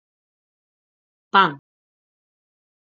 /ˈpaŋ/